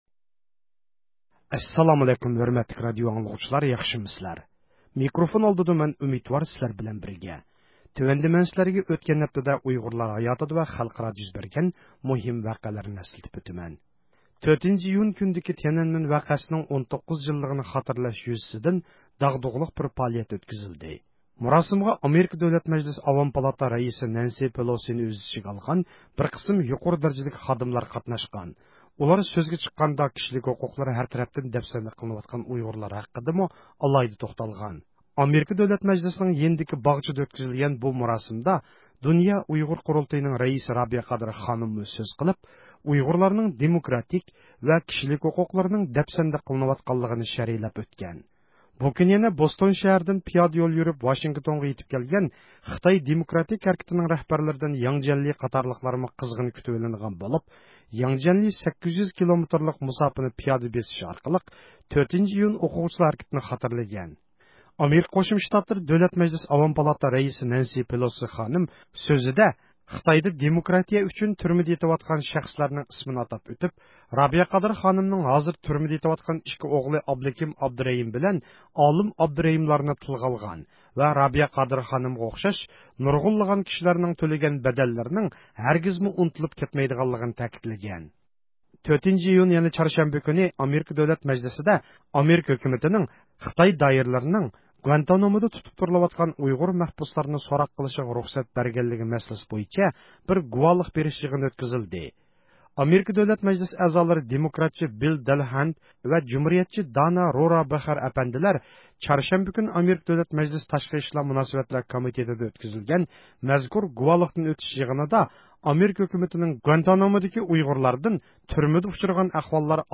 ھەپتىلىك خەۋەرلەر ( 31 – مايدىن 6 – ئىيۇنغىچە ) – ئۇيغۇر مىللى ھەركىتى